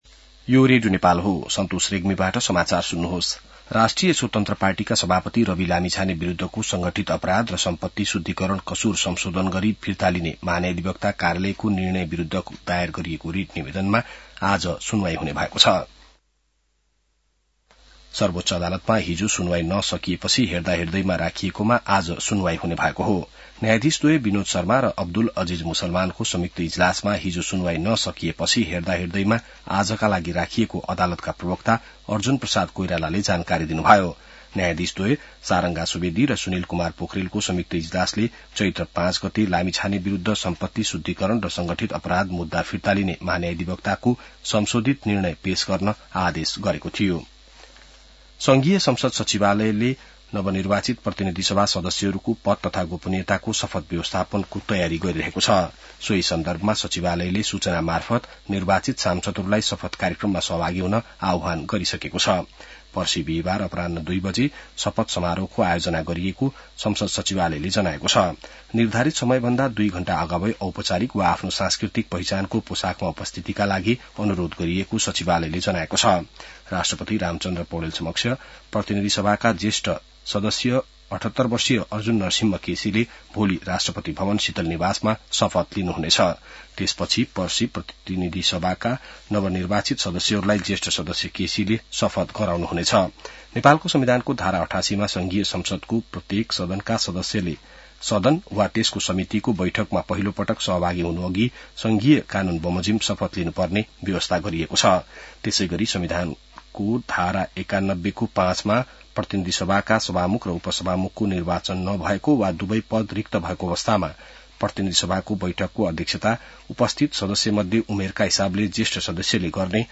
बिहान ६ बजेको नेपाली समाचार : १० चैत , २०८२